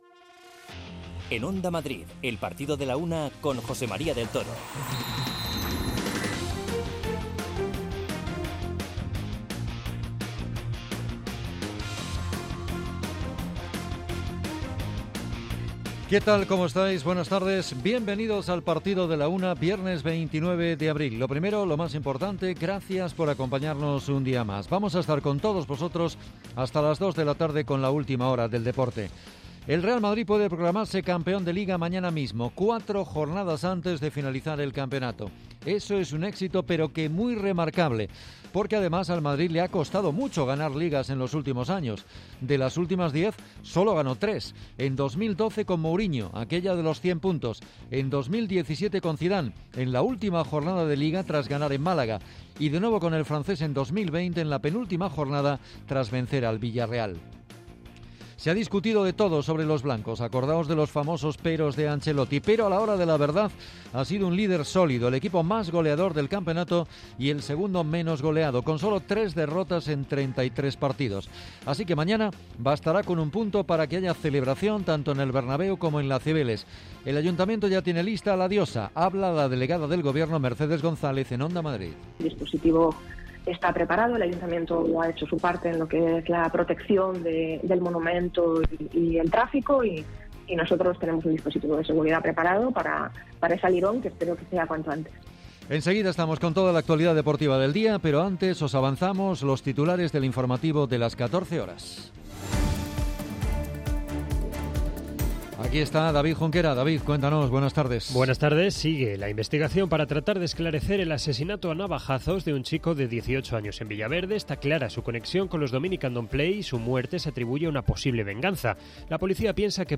Arrancamos escuchando al entrenador del Real Madrid, Carlo Ancelotti, en la rueda de prensa previa al partido contra el Espanyol. A los blancos les basta con un punto para ser campeones de Liga.